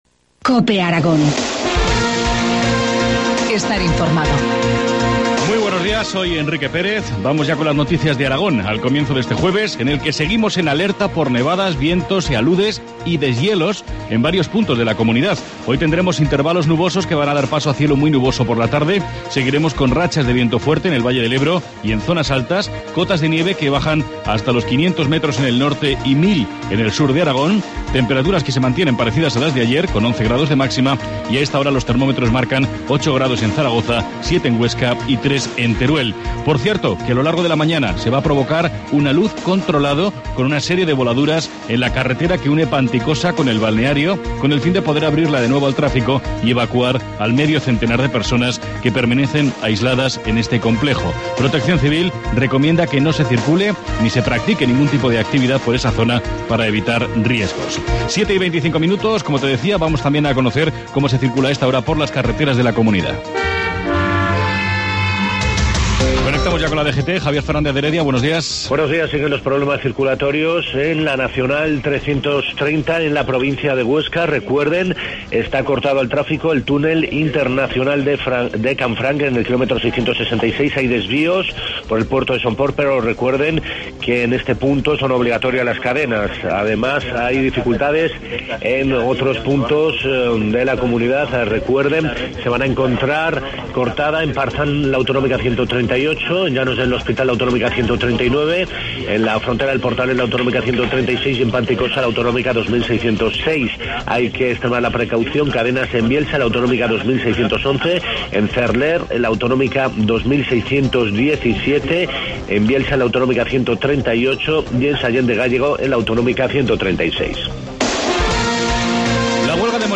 Informativo matinal, jueves 17 de enero, 7. 25 horas